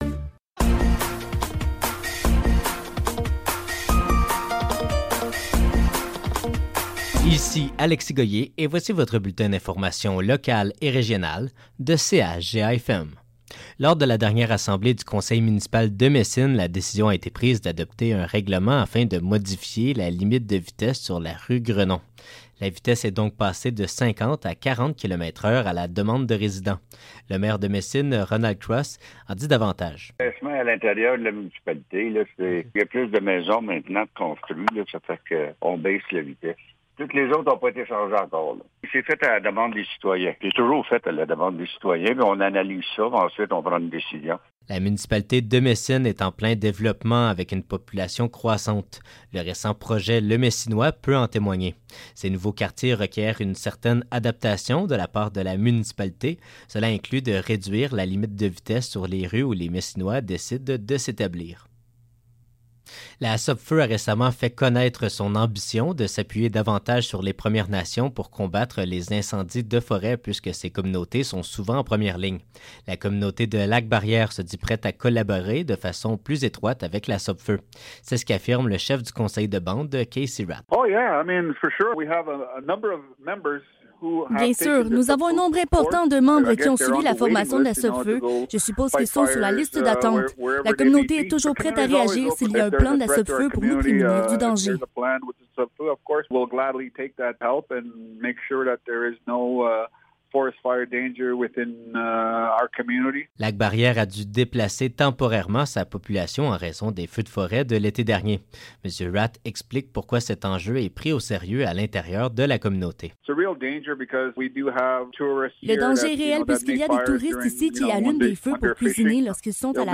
Nouvelles locales - 19 juillet 2024 - 12 h